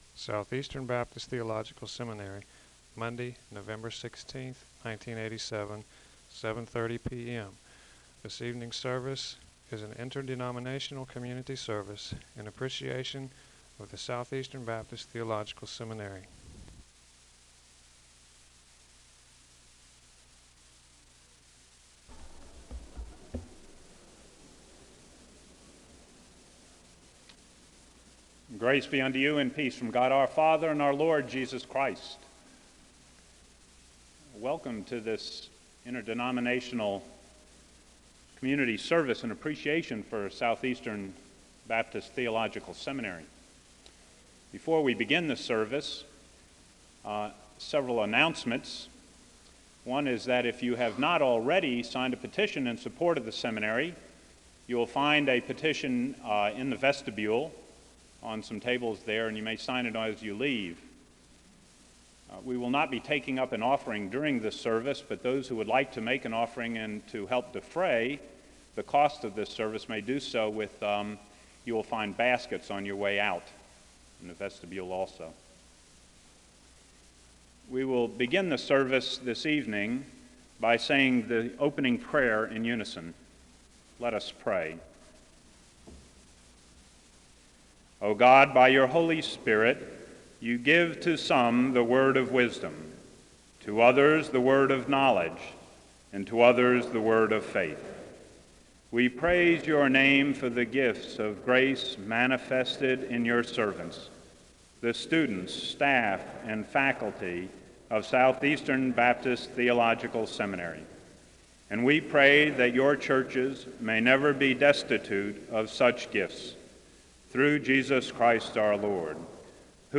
SEBTS Event - Interdenominational Service November 16, 1987
This is an interdenominational service in appreciation for Southeastern Baptist Theological Seminary. The service begins with a welcome and announcements (0:00-1:26).